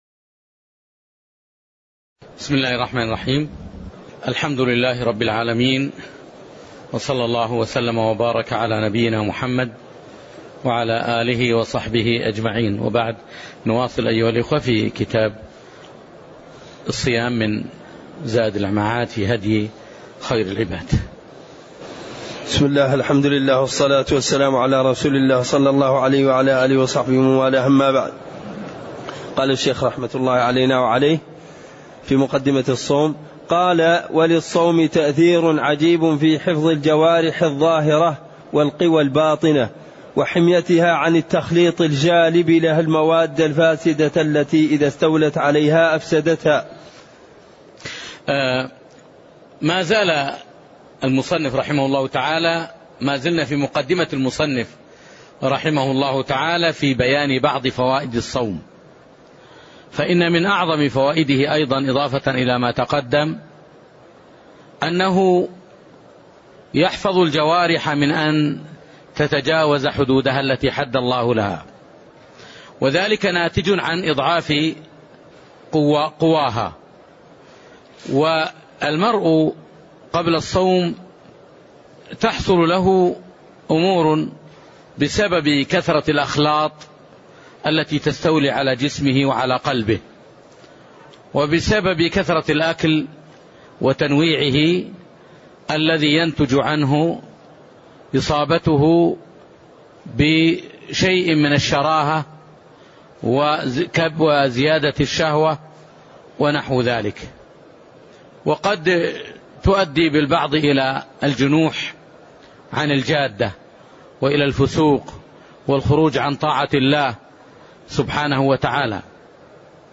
تاريخ النشر ٥ رمضان ١٤٣٠ هـ المكان: المسجد النبوي الشيخ